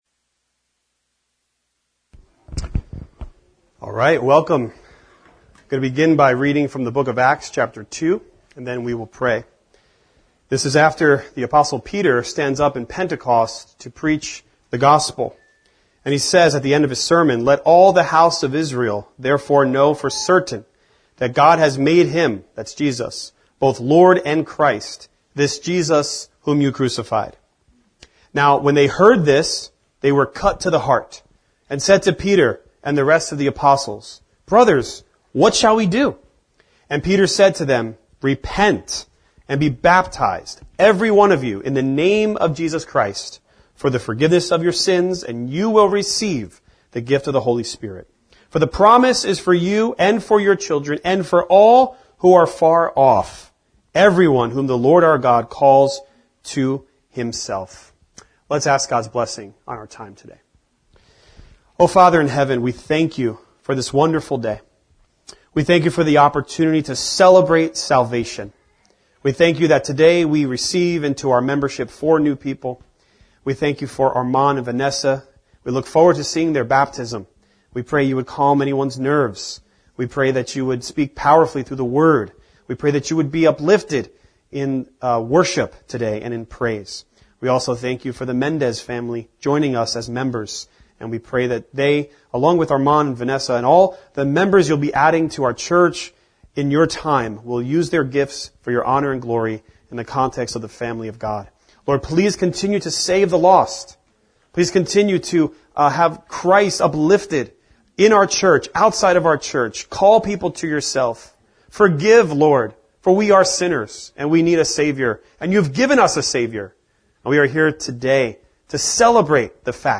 Listen to the testimonies of your new brothers and sisters in Christ - 2 baptisms and a new member family. Testimonies begin at around the 35 minute mark and water baptisms follow.